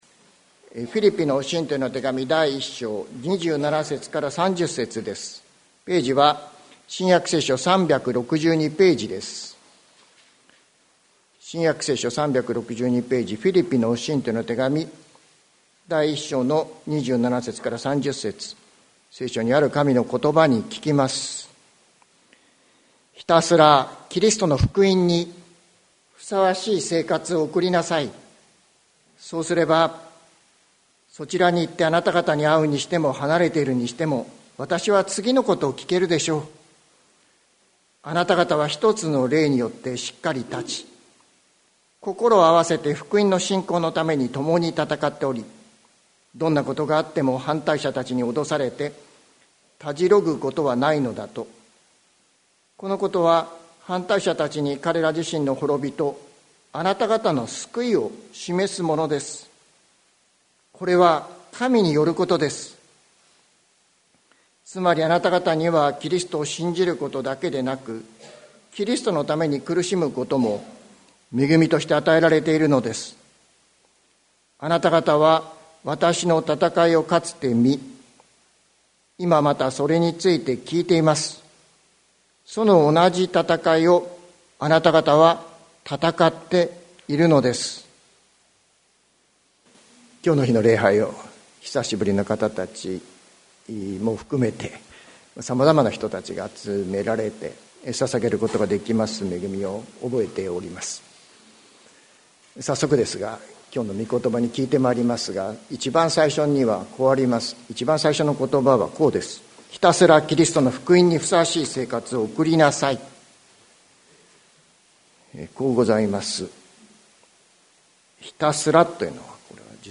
2024年05月05日朝の礼拝「ひたすら福音を生きよう」関キリスト教会
説教アーカイブ。